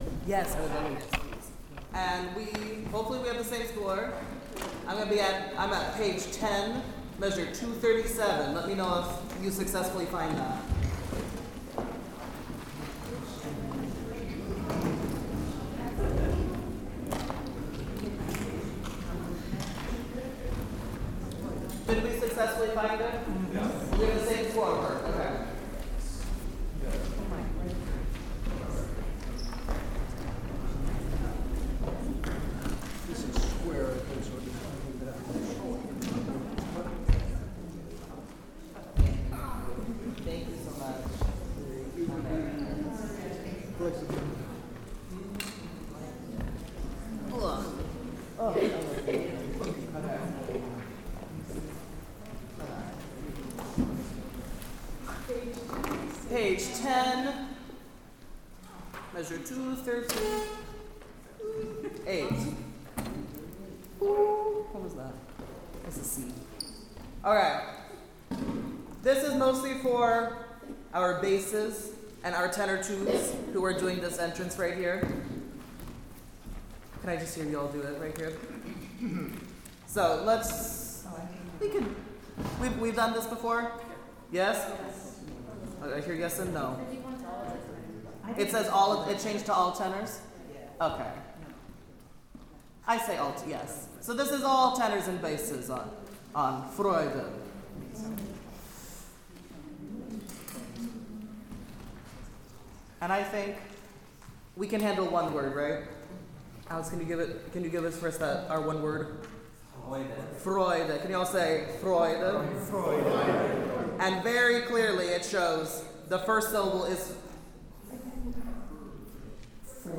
The rehearsal was recorded in two parts.
(FYI there is some background noise from an instrumental rehearsal in an adjoining room.)